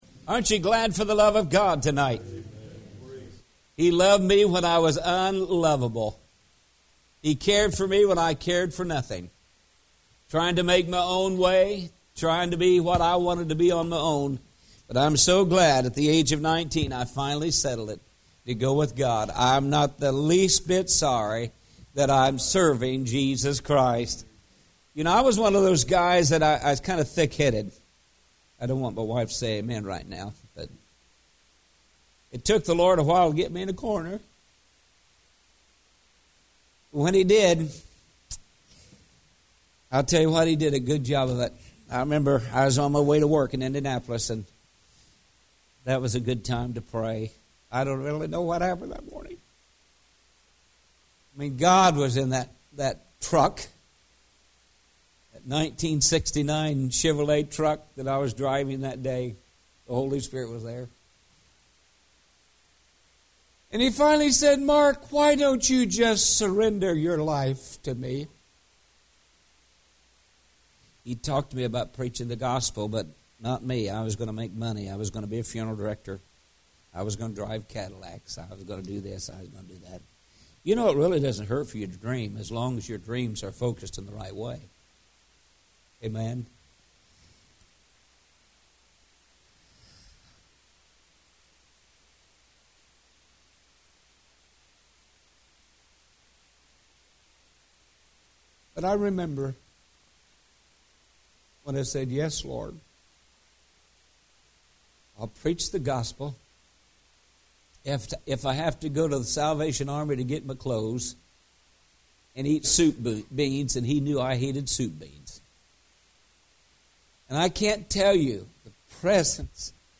Series: Spring Revival 2017